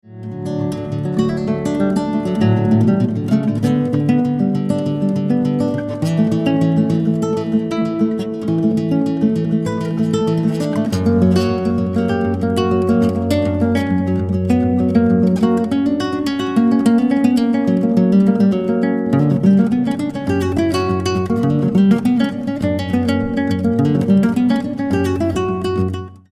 Instrumental Acoustic Guitar